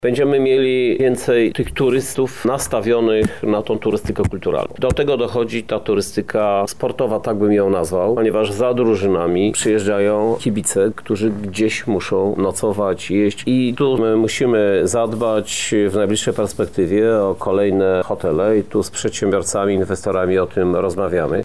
„Europejska Stolica Kultury wybuchnie nam w 2029 roku, ale od przyszłego roku będzie tych projektów inicjowanych przez nas coraz więcej” – tłumaczy Krzysztof Żuk, Prezydent Miasta Lublin: